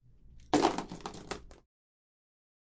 掉落的锅碗瓢盆
描述：我把一些锅碗瓢盆扔到水泥地上，为我正在制作的一个视频获得背景声音。 该曲目包括7个不同的元素。 不打算一起使用，但这样提供了各种相似但不同的声音。
标签： 碰撞 跌落
声道立体声